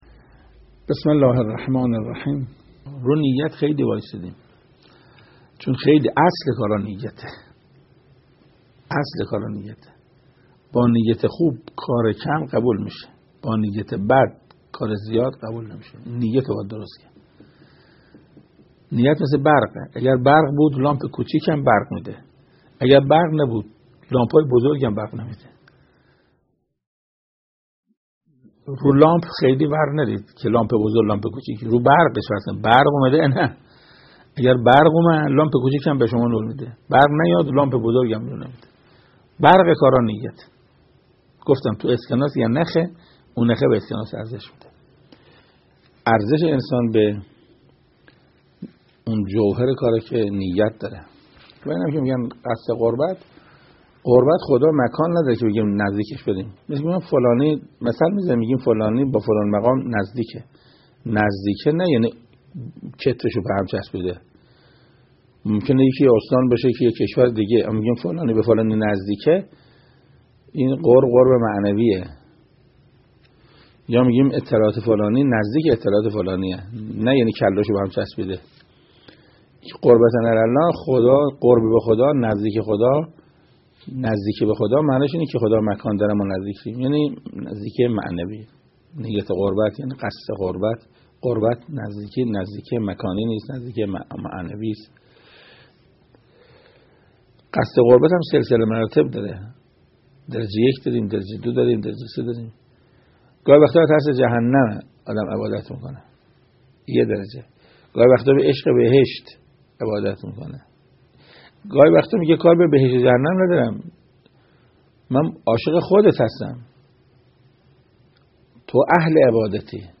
جایگاه نیت در نماز با سخنرانی استاد قرائتی(4)